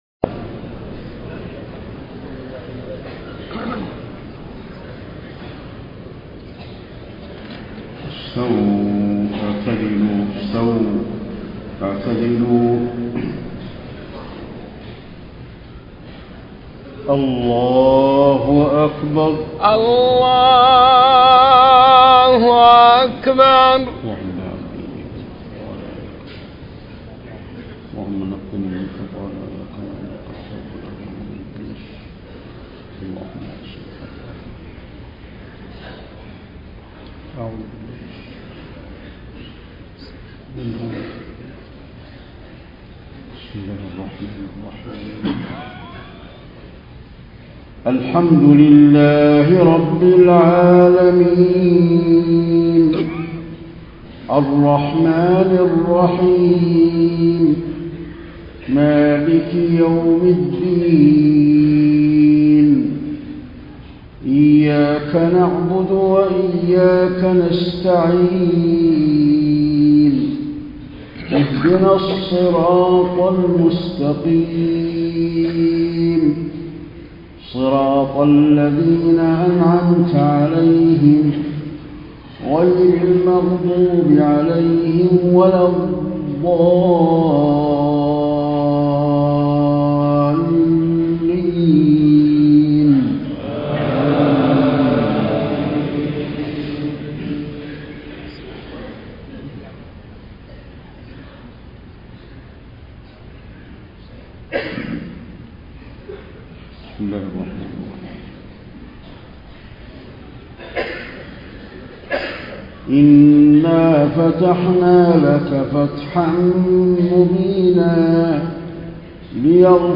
صلاة المغرب ١ محرم ١٤٣٤هـ من سورة الفتح | > 1434 🕌 > الفروض - تلاوات الحرمين